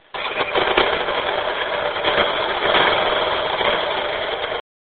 Petit quizz: A quelle moto appartiennent ces bruits?
moto_1.mp3